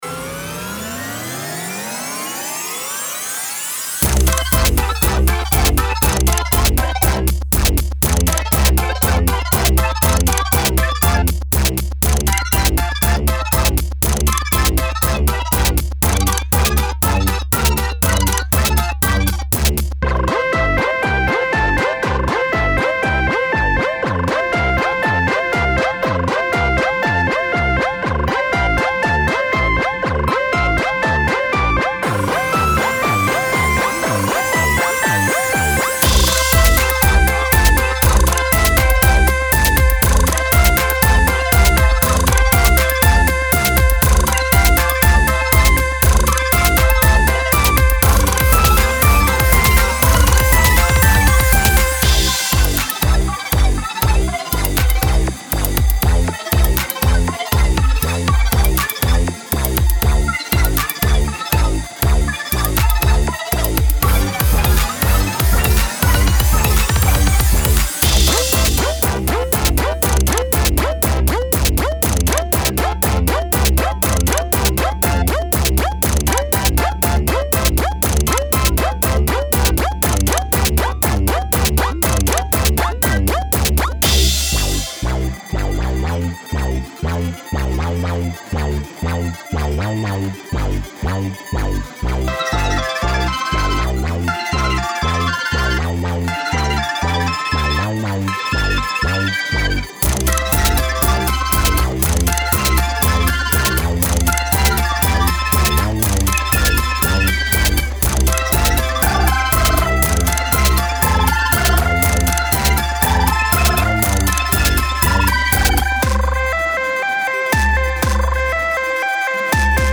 Style Style EDM/Electronic, Other
Mood Mood Bright, Funny
Featured Featured Drums, Synth
BPM BPM 120